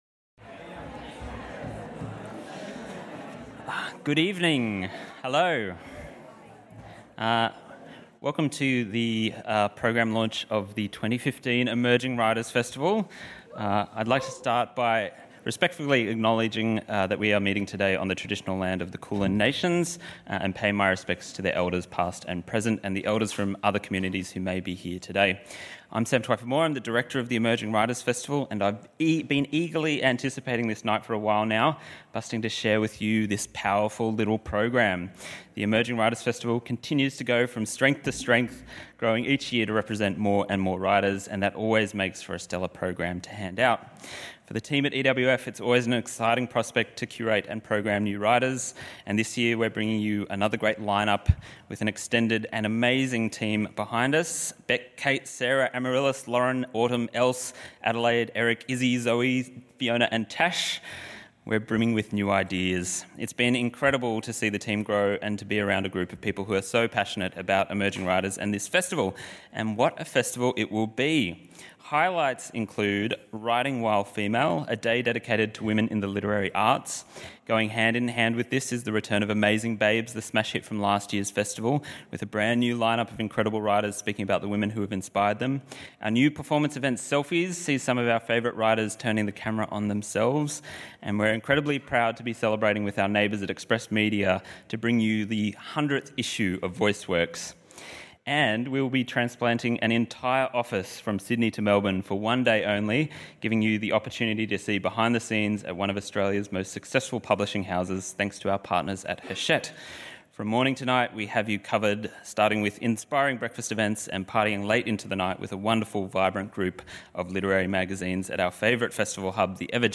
As four guests from this year’s Emerging Writers’ Festival present their writing, it’s up to you to decide if the piece has previously been accepted or rejected for publication.